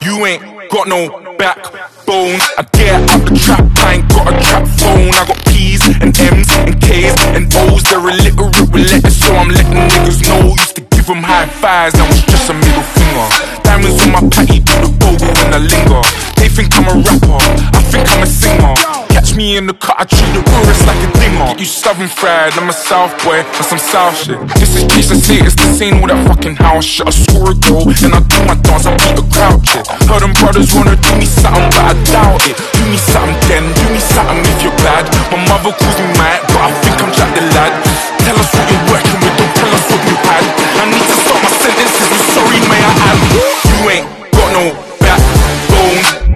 Bridgeport BR2J2 Milling Machine Available sound effects free download
Variable Speed Head & Power Feed Great addition to a toolroom, fabricators or home shop!